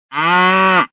mugido
mugido.mp3